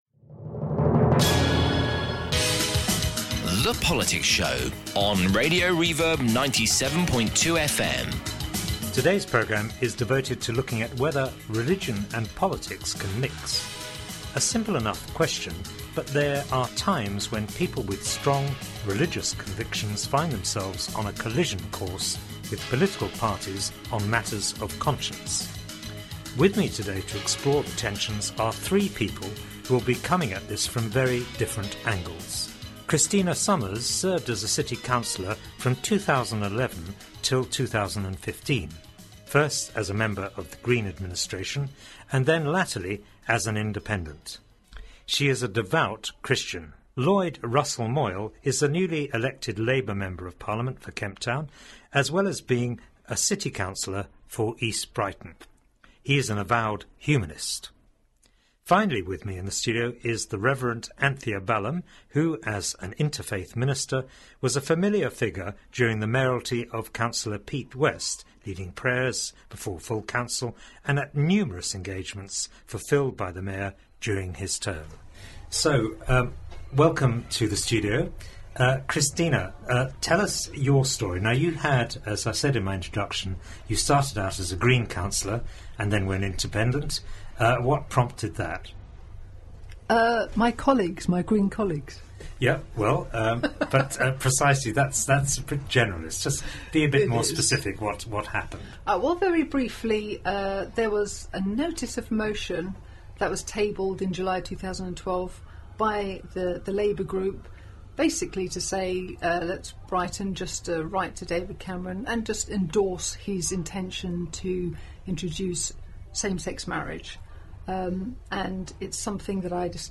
The Reverb Politics Show, presented by former Brighton and Hove councillor Geoffrey Bowden, puts the men and women making the decisions under the spotlight.